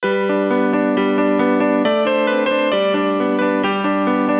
piano-mono.wav